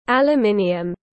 Nhôm tiếng anh gọi là aluminium, phiên âm tiếng anh đọc là /ˌæləˈmɪniəm/.
Aluminium /ˌæləˈmɪniəm/